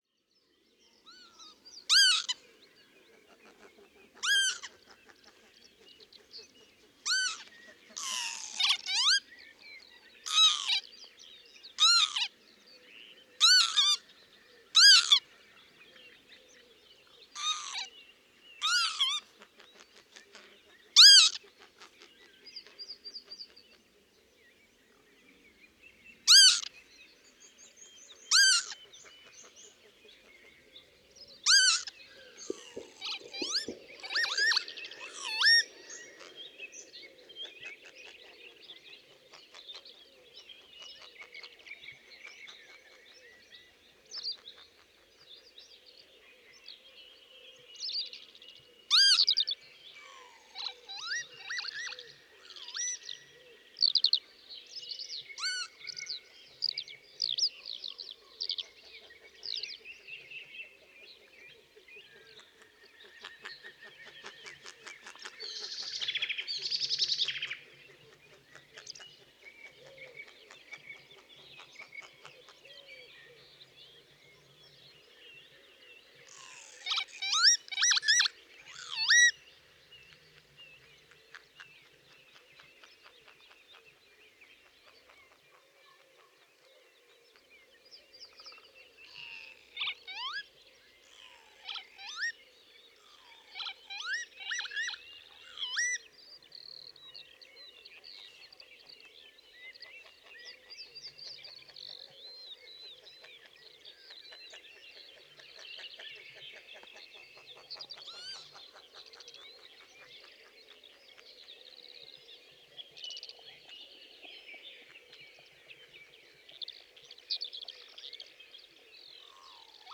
Avefría choromica
Canto